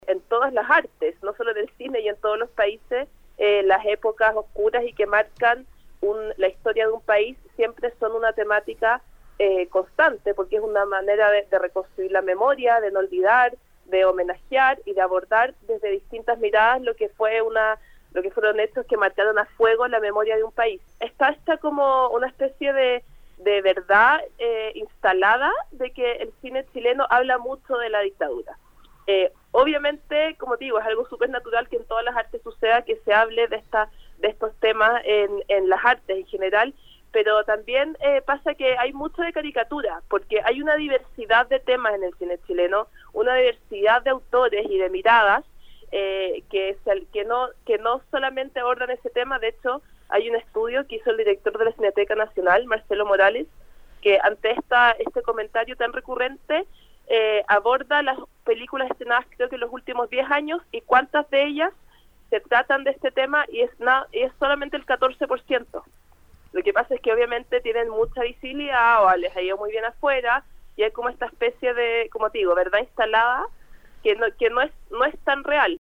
En entrevista con Nuestra Pauta